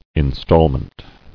[in·stall·ment]